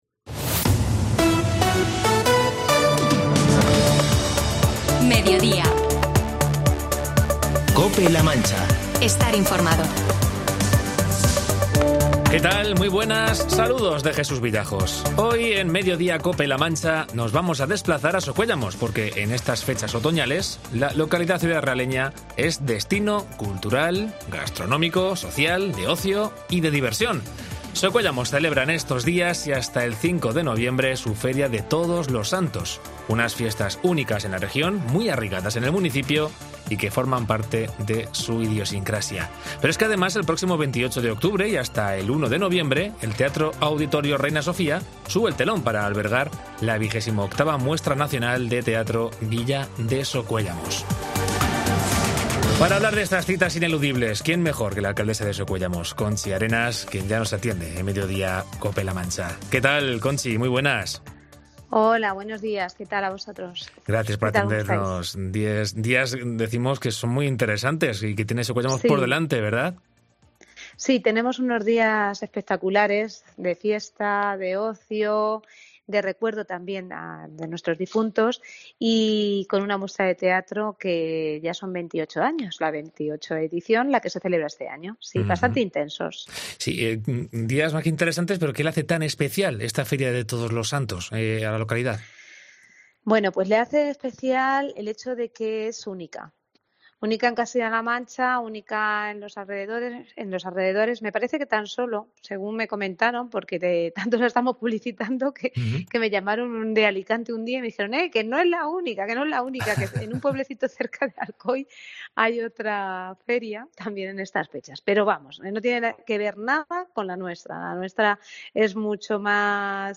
Entrevista a Conchi Arenas, alcaldesa de Socuéllamos con motivo de su popular Feria de Todos los Santos y la XXVIII Muestra Nacional de Teatro "Villa de Socúellamos"